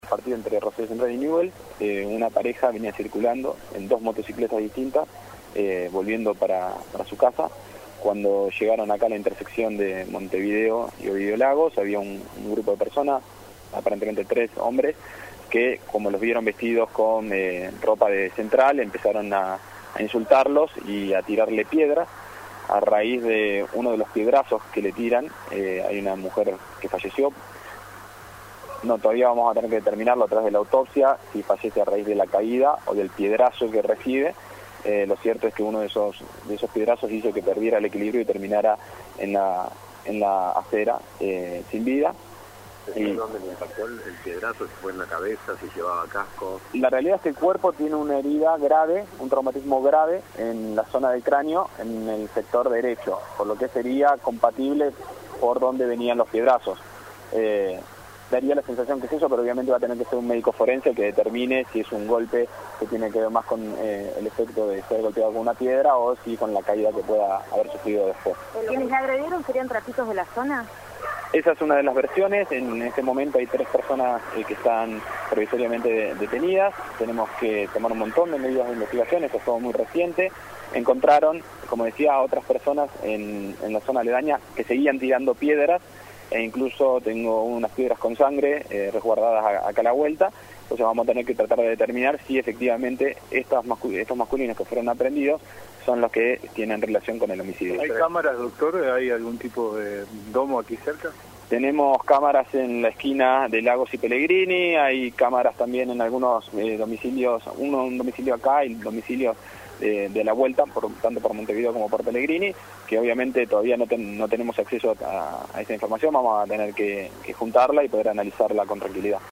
el fiscal que investiga el caso, Gastón Ávila, dio detalles del hecho al móvil de LT3. Entre otras cosas manifestó que la autopsia determinará si la joven falleció por el piedrazo o por la caída y destacó que el cuerpo presenta un grave traumatismo en el sector derecho de la cabeza.